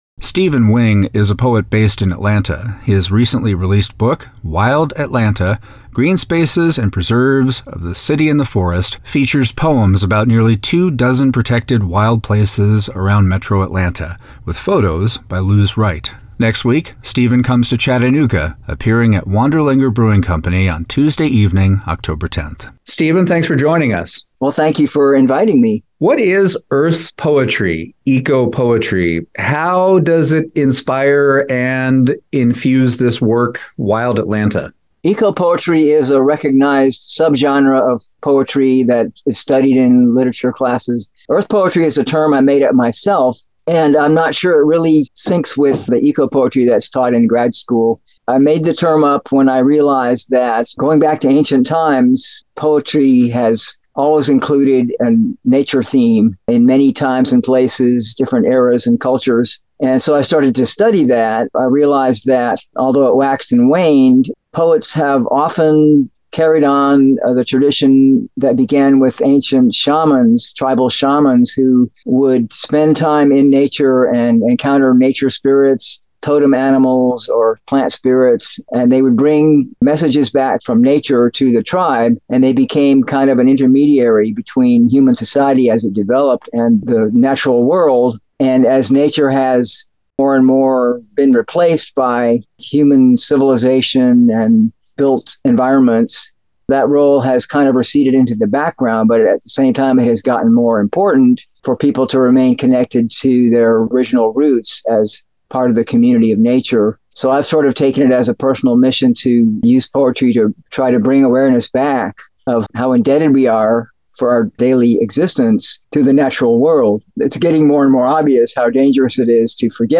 WUTC Chattanooga interview
WUTC-interview-Chattanooga-.mp3